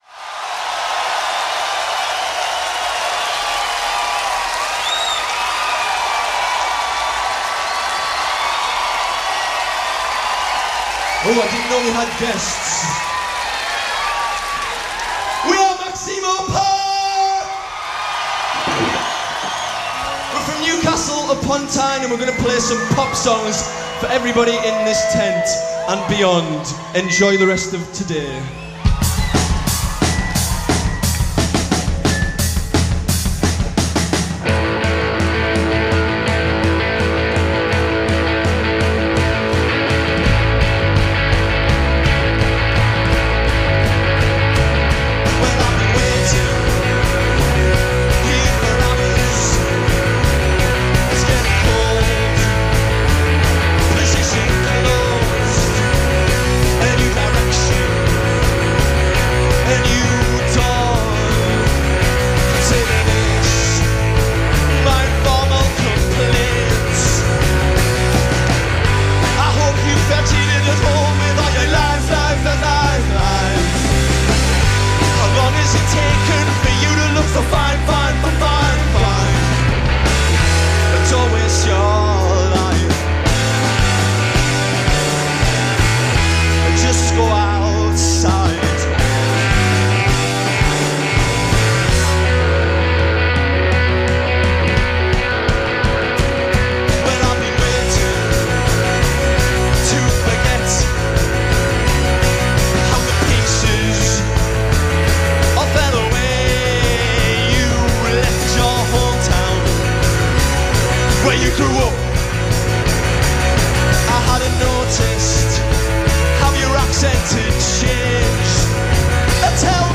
vocals
guitar
keyboard
drums